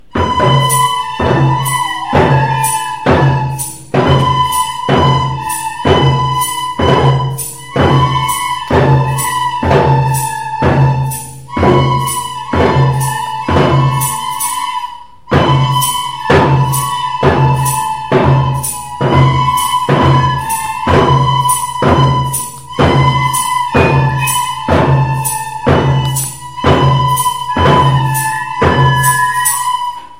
Polca-5è.mp3